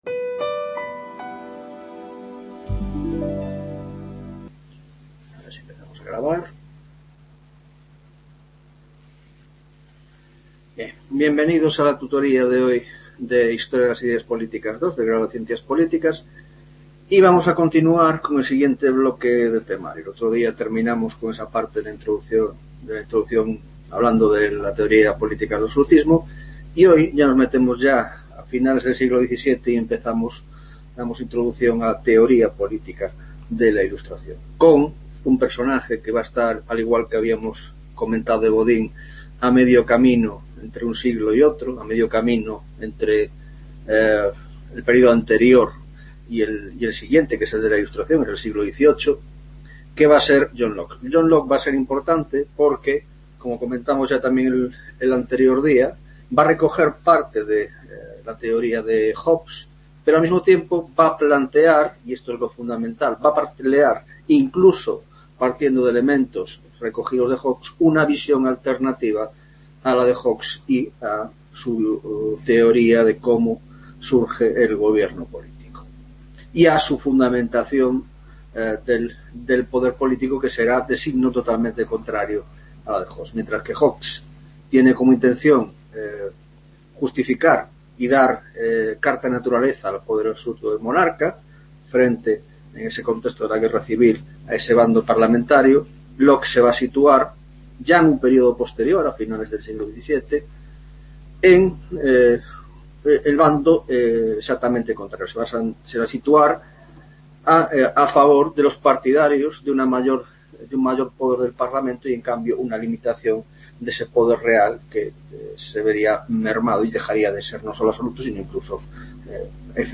3ª Tutoria de Historia de las Ideas Políticas 2